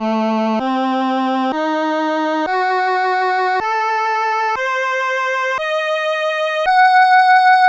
My first multisampled instrument - very rare and unique Vermona Piano Strings keyboard a.k.a. “poor man’s” ARP Solina, it’s strings (dark fast chorus variant), in mono: